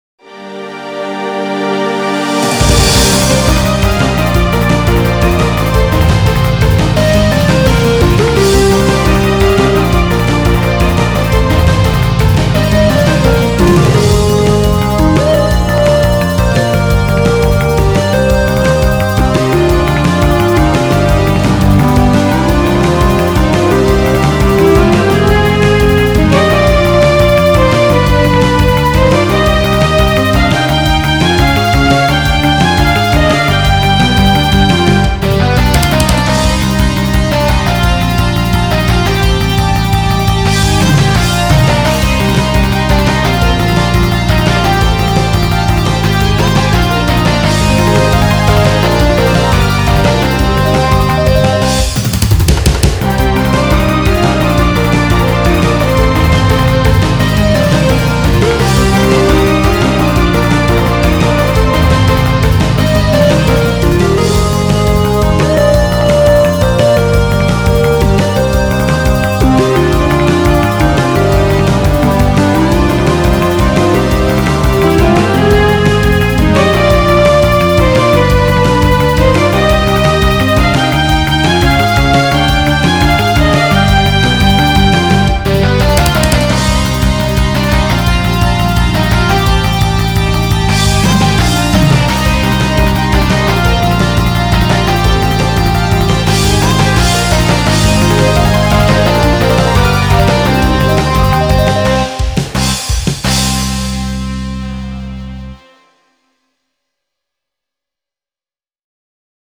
久しぶりに、ミミコピアレンジをしてみました。
某アクションゲームのステージ曲です。
その名も「稲妻アルペジオ」といいます。
その場所のコード構成音をBPM140オーバーで
下から駆け上がり、今度は上から一気に下がり、を何度か繰り返すこと。
ちなみに、音色もだんだん変化させるというのがミソ。
結構、感触としてはハデなので、しくじるとダサいｗ